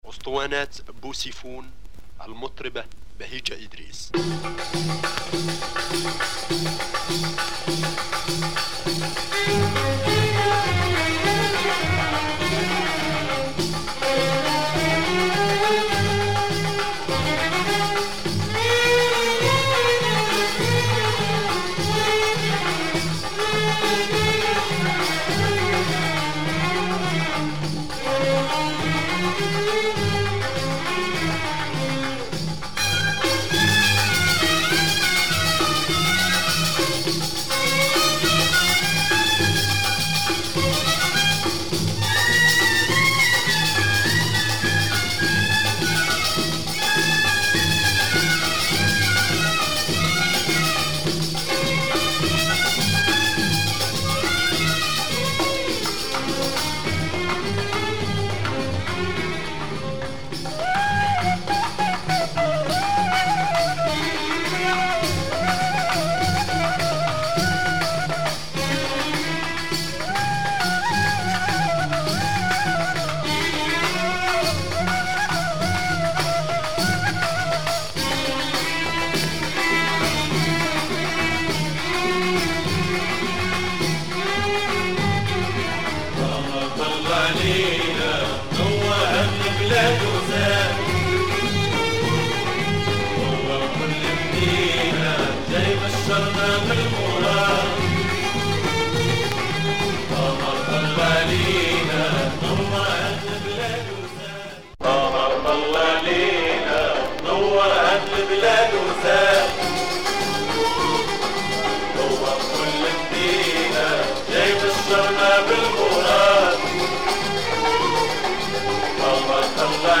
Moroccan female singer